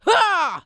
CK发力03.wav
人声采集素材/男2刺客型/CK发力03.wav